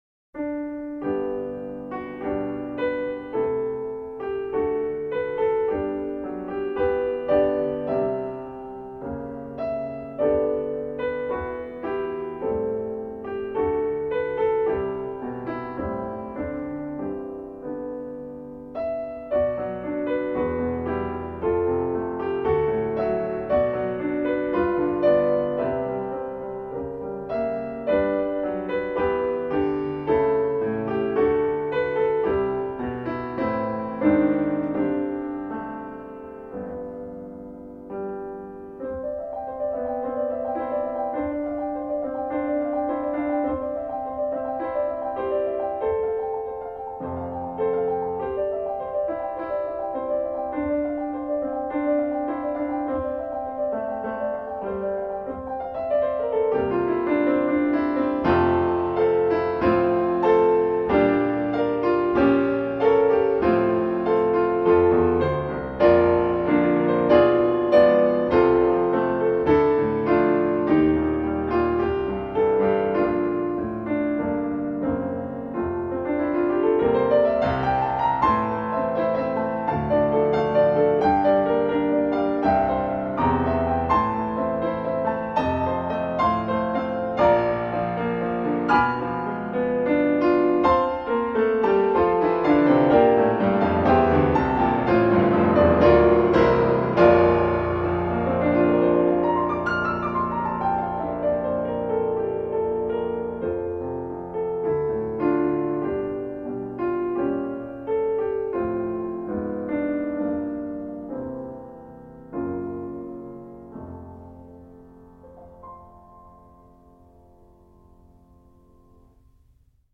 (performance)